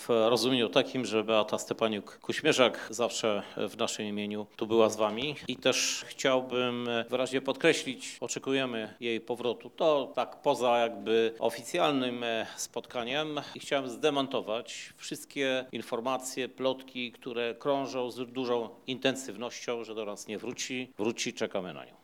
Prezydent Lublina Krzysztof Żuk poinformował podczas dzisiejszej konferencji prasowej poświęconej Nocy Kultury, że oczekuje powrotu do pracy swojej zastępczyni, Beaty Stepaniuk-Kuśmierzak.